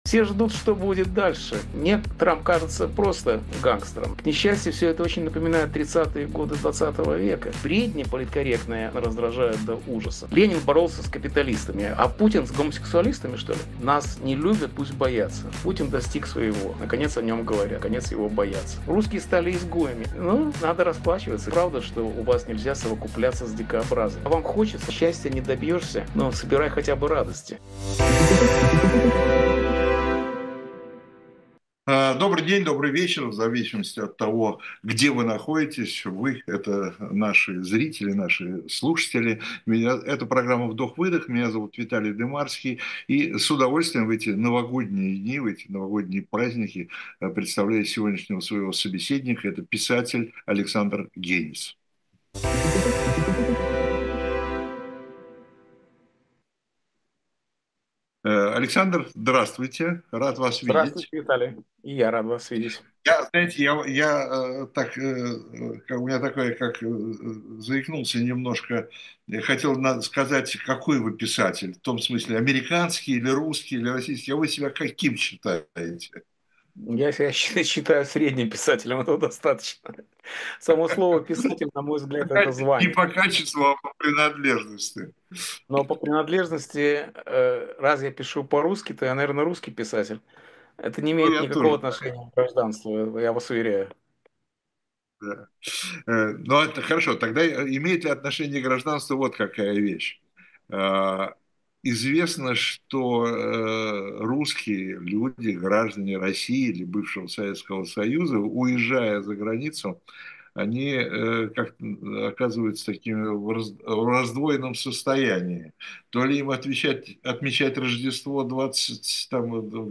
Эфир ведёт Виталий Дымарский
Александр Генис, русский писатель без российского гражданства — гость программы «Вдох-выдох».